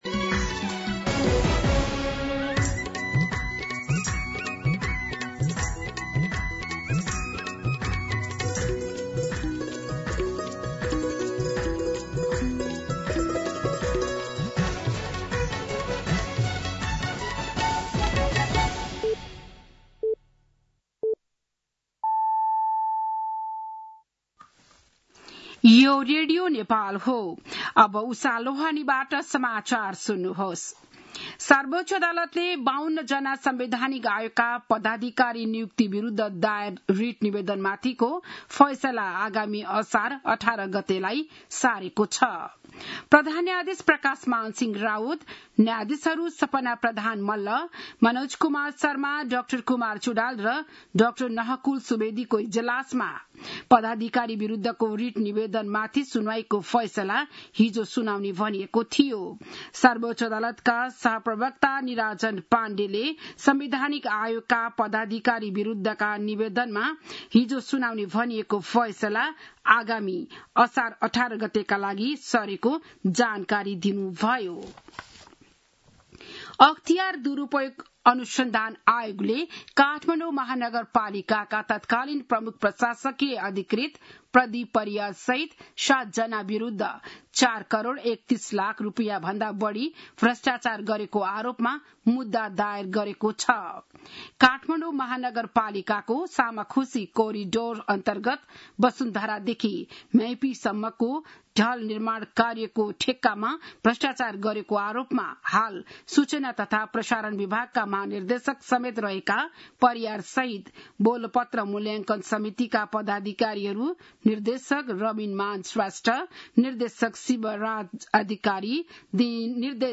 बिहान ११ बजेको नेपाली समाचार : २९ जेठ , २०८२
11-am-Nepali-News-1.mp3